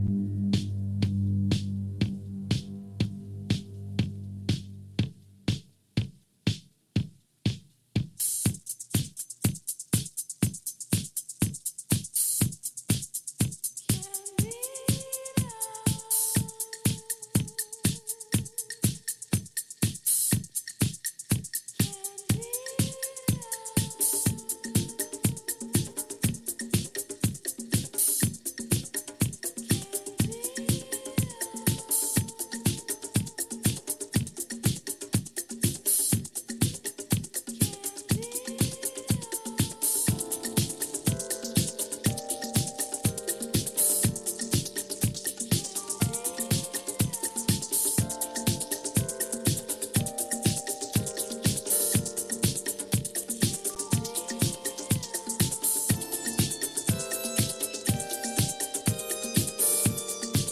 Disco
a spacey, beautiful voyage